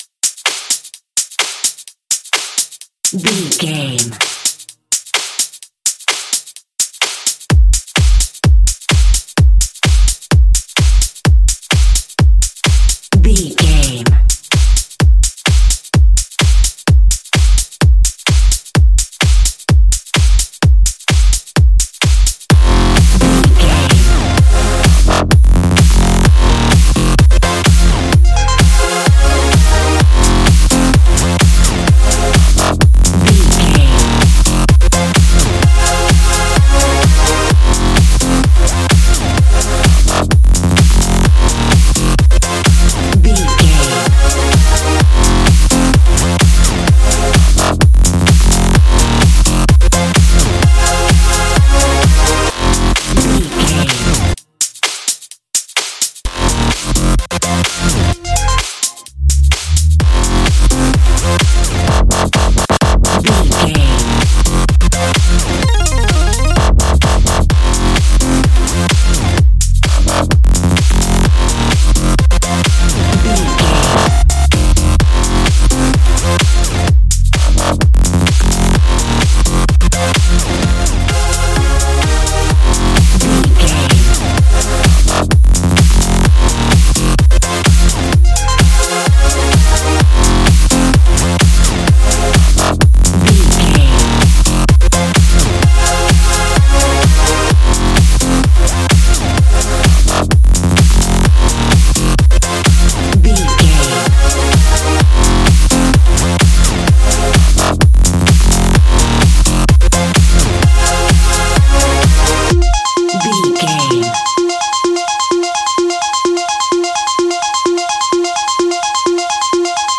Epic / Action
Fast paced
Aeolian/Minor
aggressive
powerful
dark
driving
energetic
intense
drum machine
synthesiser
breakbeat
synth drums
synth leads
synth bass